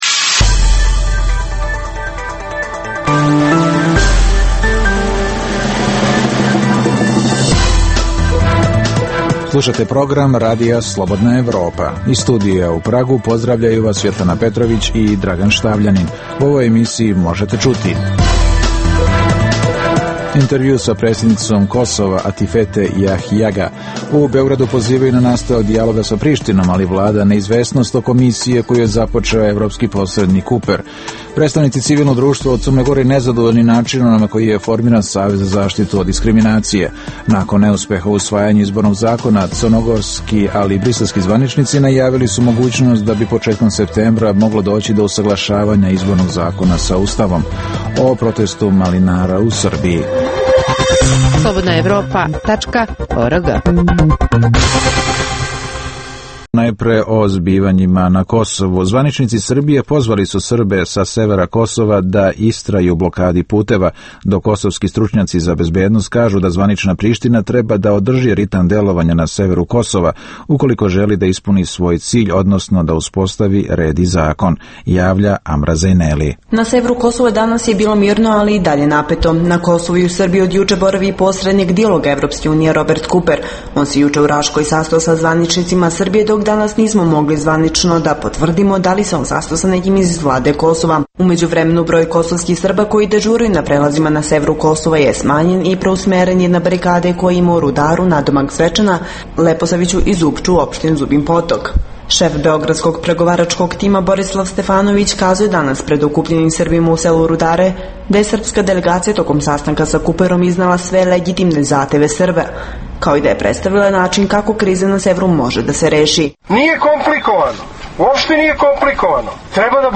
U emisiji možete čuti: - Intervju sa predsednicom Kosova Atifete Jahjaga.